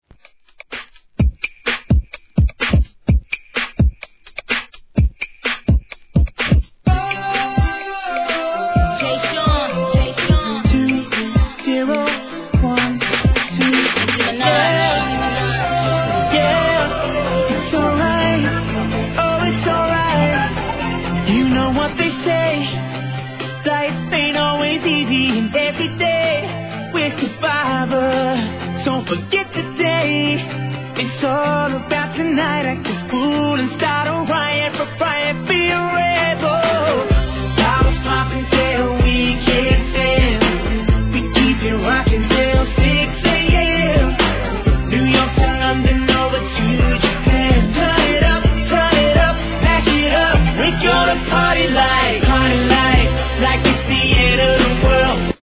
HIP HOP/R&B
BPM126